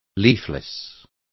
Complete with pronunciation of the translation of leafless.